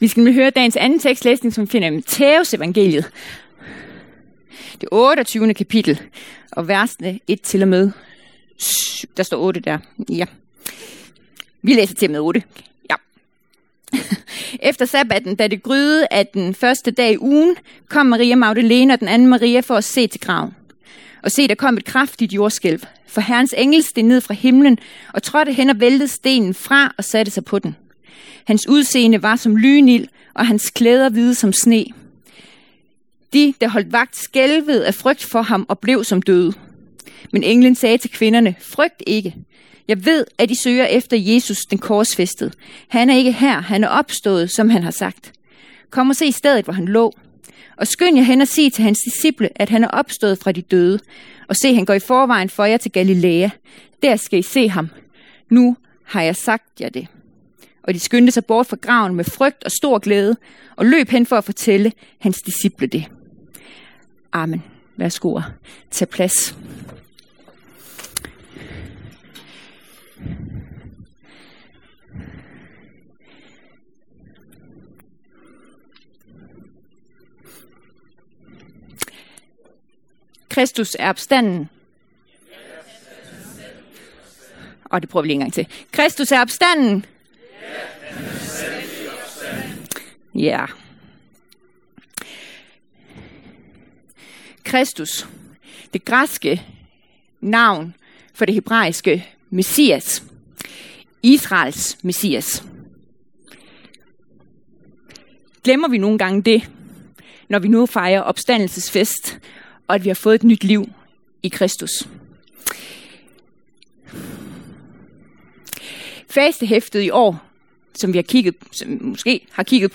Kalender 15.04 09:00 Legegruppe 15.04 15:00 Kaffe på kanden 15.04 19:00 Base Se hele kalenderen Lyt til en prædiken 05.04 Nyt liv. Påskeprædiken. 29.03 At tage imod Jesus - at svare i hengivenhed og kærlighed. 15.03 At tage imod Jesus - Livets brød.